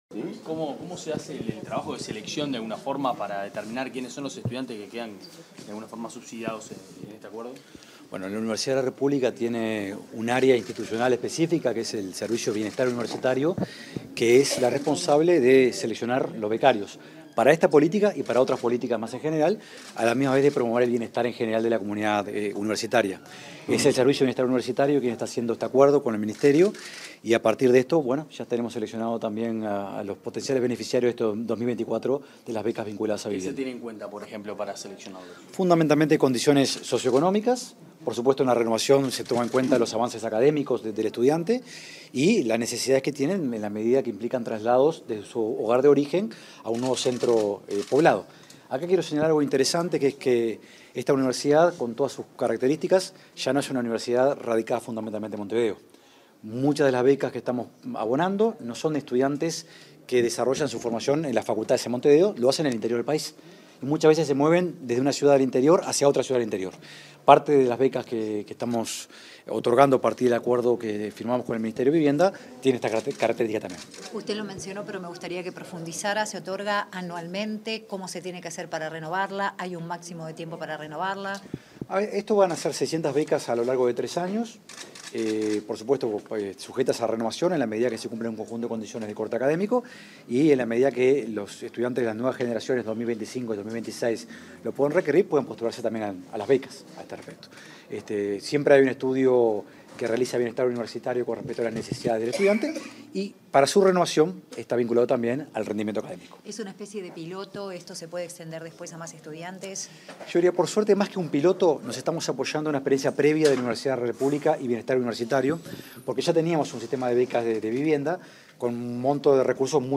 Declaraciones del rector de la Udelar, Rodrigo Arim
Declaraciones del rector de la Udelar, Rodrigo Arim 21/06/2024 Compartir Facebook X Copiar enlace WhatsApp LinkedIn Tras la firma de un convenio con el Ministerio de Vivienda y Ordenamiento Territorial (MVOT) para facilitar la garantía de alquiler a estudiantes, este 21 de junio, el rector de la Universidad de la República (Udelar), Rodrigo Arim, realizó declaraciones a la prensa.